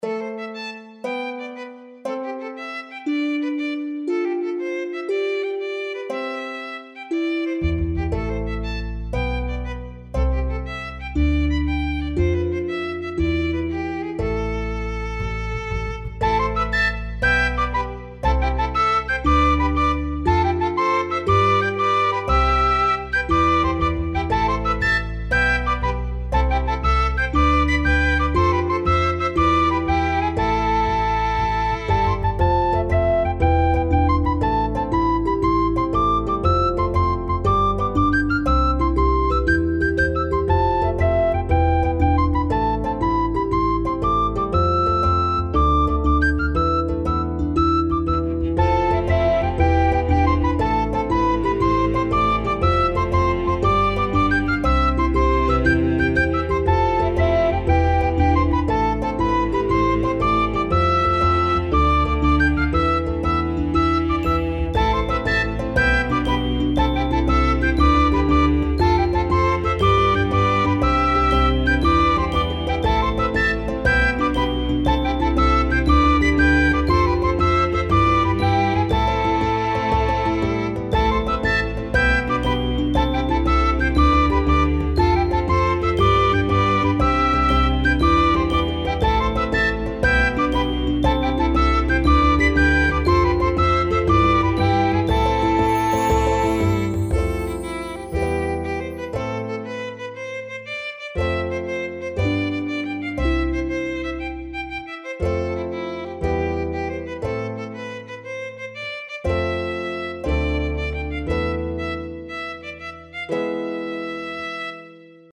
ファンタジー系フリーBGM｜ゲーム・動画・TRPGなどに！
ちょっと田舎っぽいけどみんなパワフルなんだろな。